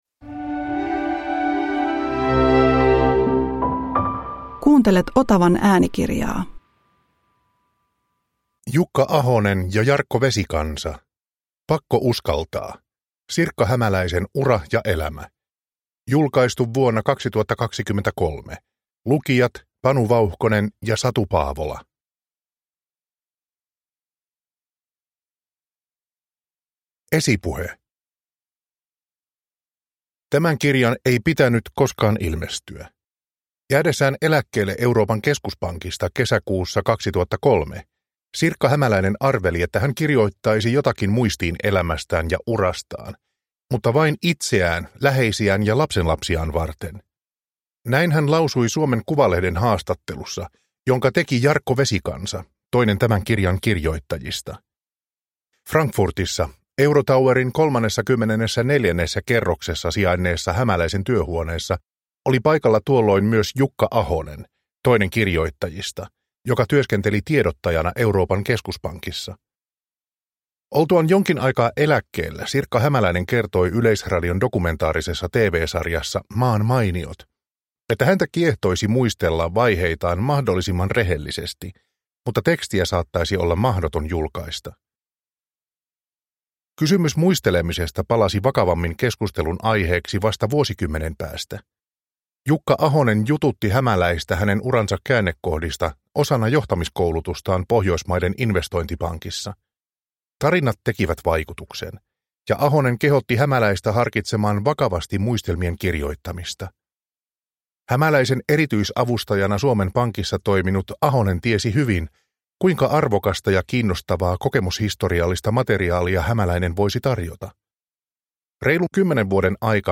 Pakko uskaltaa – Ljudbok – Laddas ner